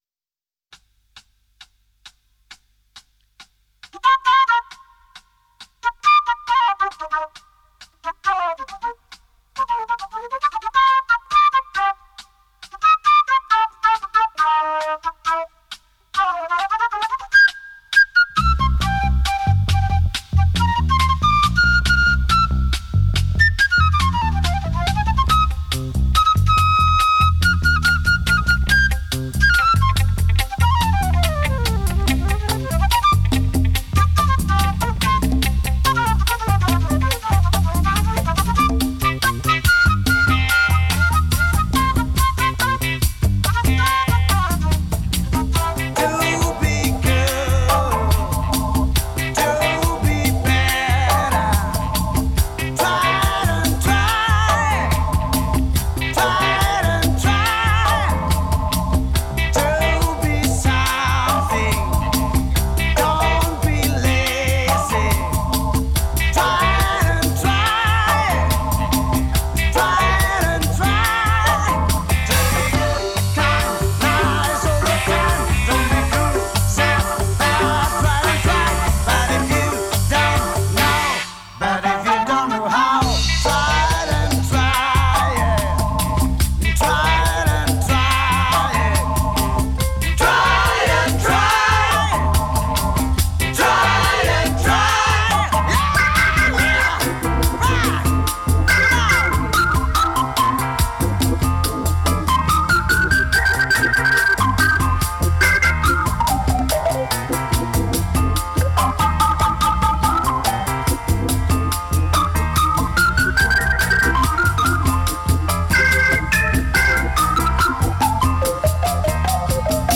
играющая в стиле R & B и Funk Rock